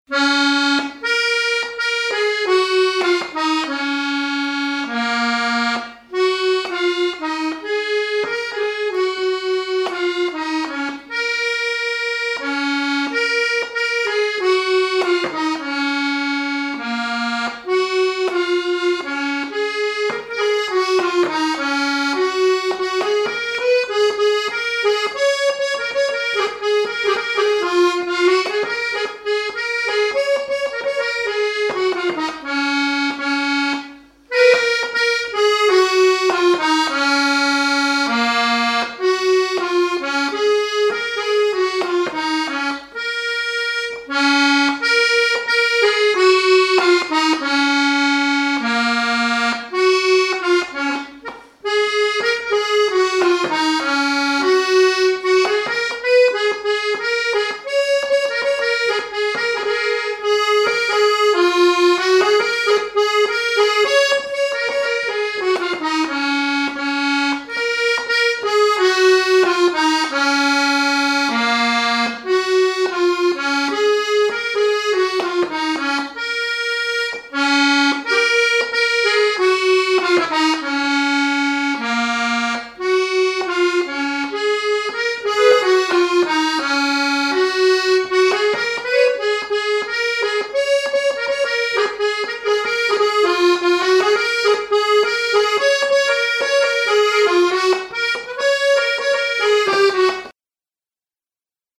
marche de cortège de noces
instrumental
Enquête Arexcpo en Vendée-Pays Sud-Vendée
Pièce musicale inédite